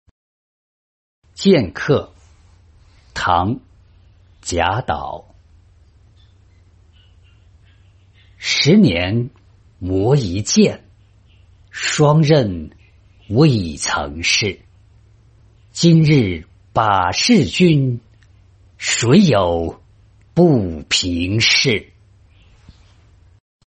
剑客-音频朗读